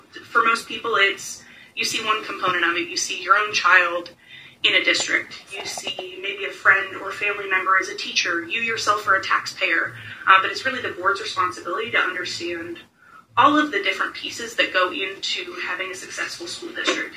During her interview, Dellafiora said that the biggest thing in serving on the school board was the opportunities they have to see the work done in the schools.